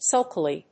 音節sulk･i･ly発音記号・読み方sʌ́lkɪli